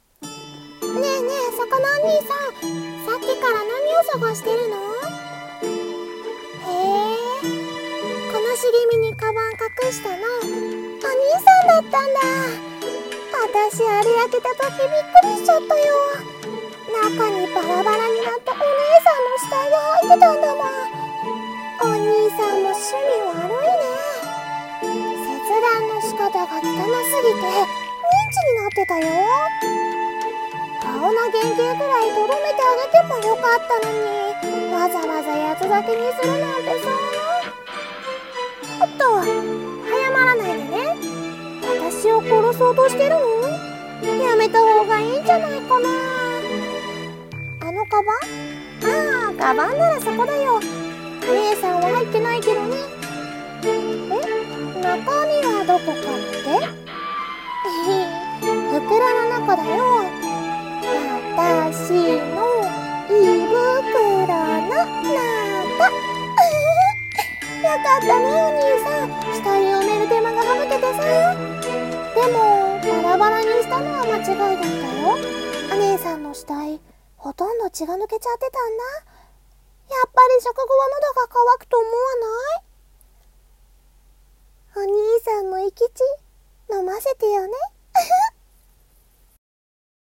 【声劇用台本】バラバラ死体の夜